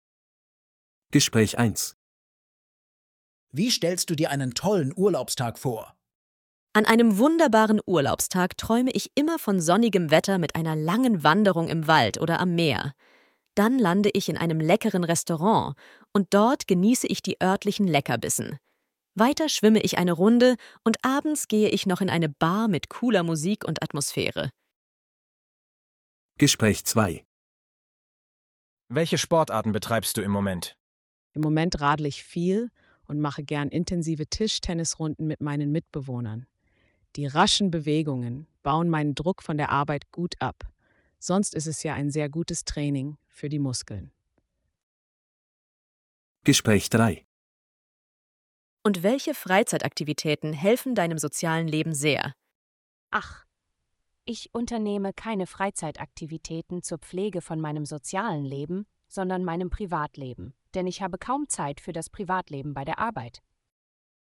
Bài nghe dành cho các cuộc hội thoại trong bài tập số 4 này: